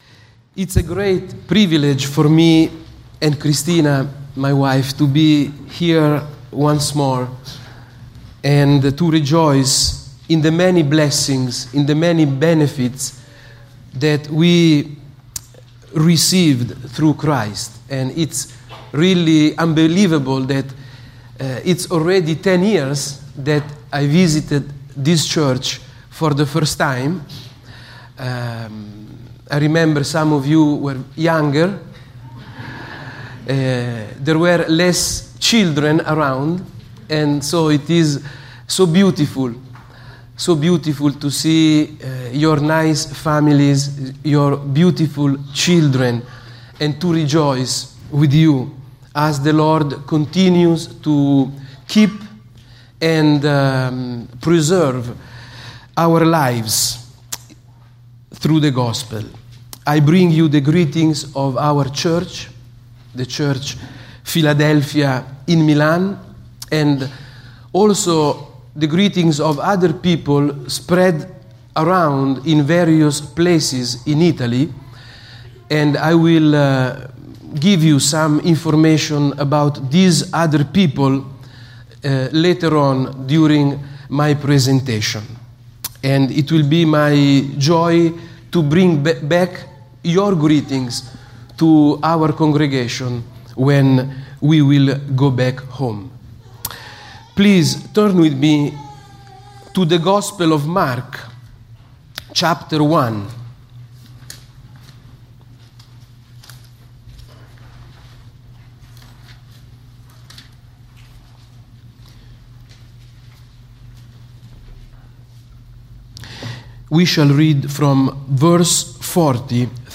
Mark — Sermons — Christ United Reformed Church